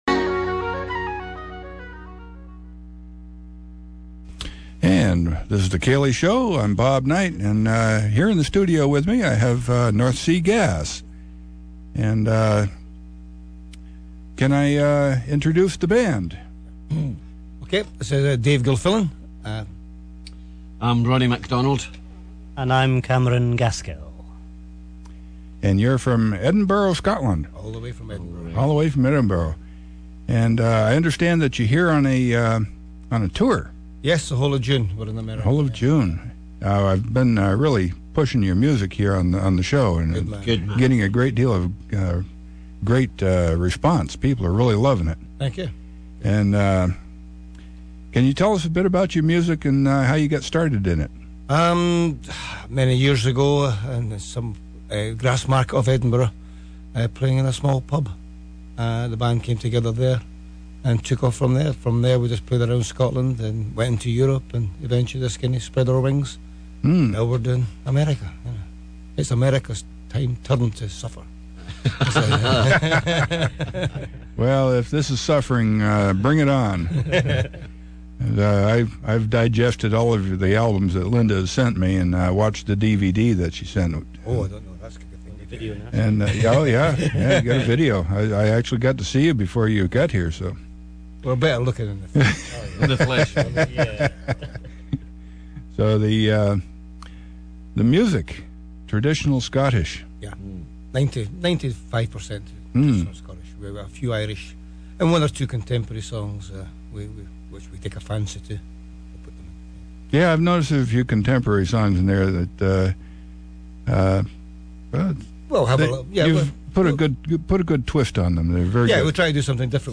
nsg_interview_no_songs.mp3